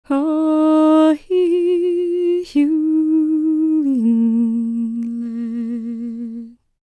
L  MOURN A07.wav